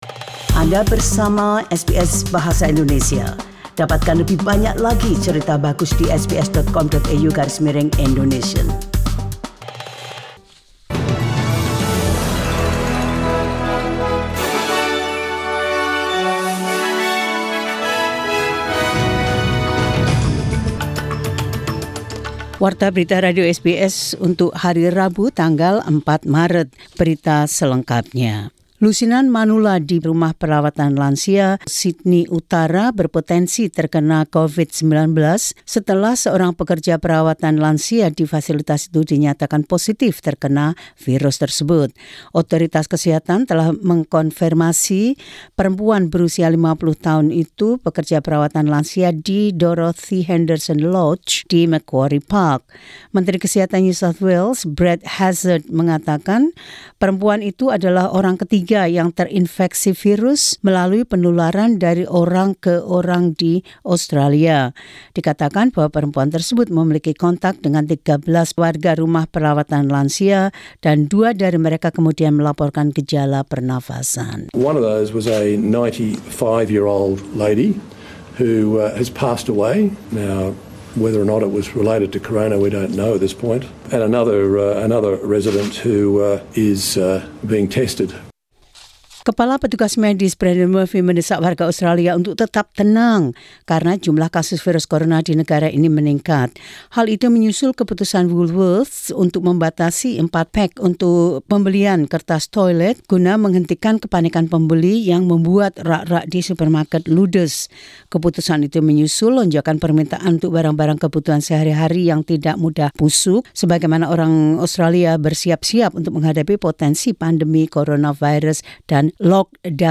SBS Radio News in Indonesian 4 Mar 2020.